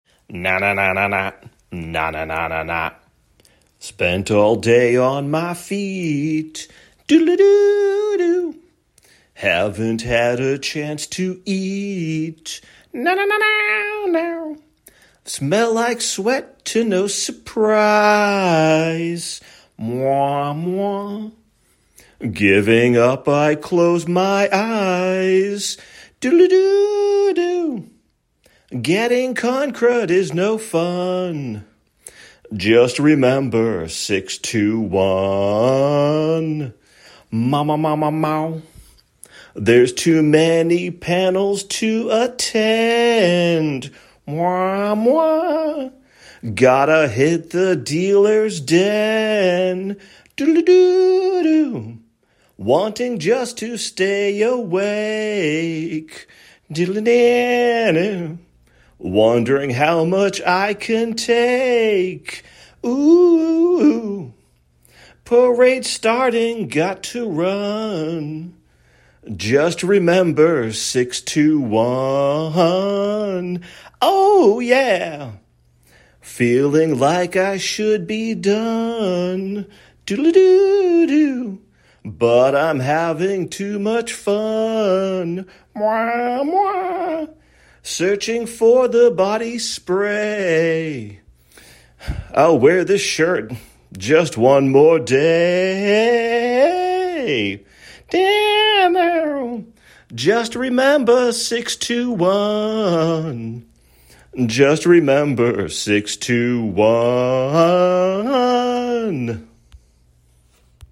Another bad attempt at singing one of my parody songs.